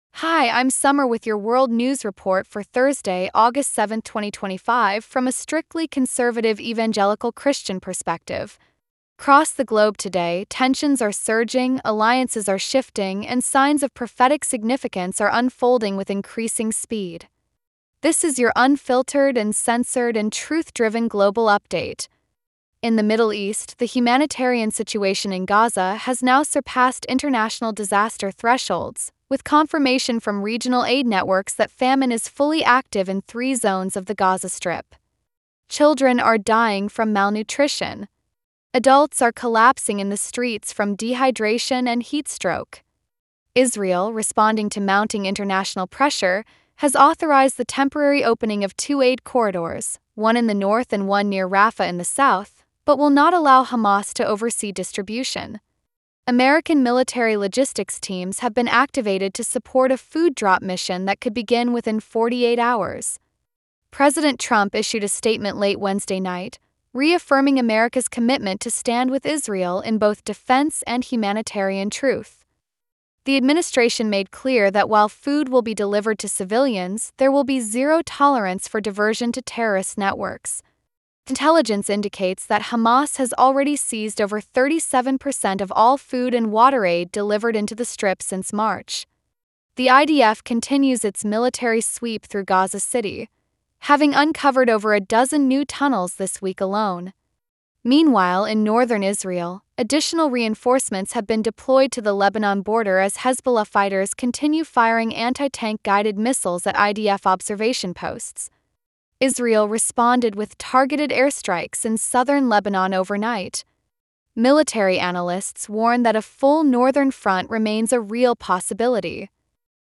This is your unfiltered, uncensored, and Truth-driven global update. In the Middle East, the humanitarian situation in Gaza has now surpassed international disaster thresholds, with confirmation from regional aid networks that famine is fully active in three zones of the Gaza Strip.